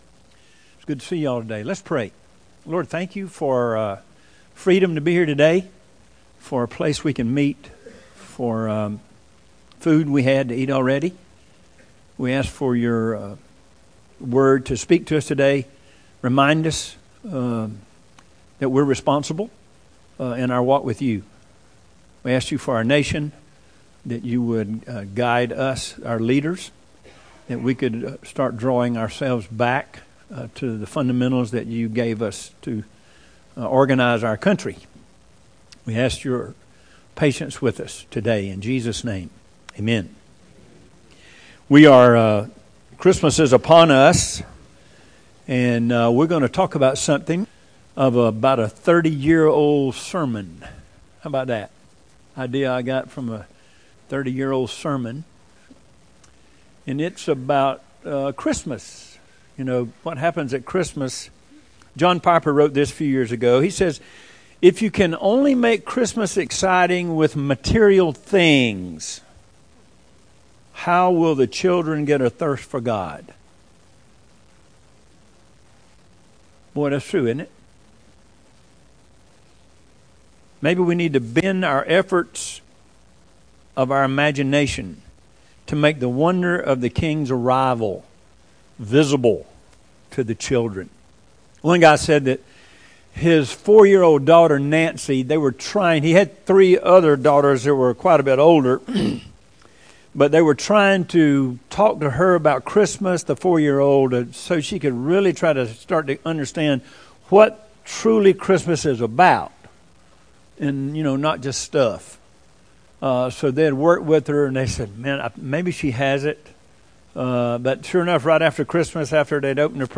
Weekly sermons from Harmony Community Church in Byron, Georgia.
1215Sermon.mp3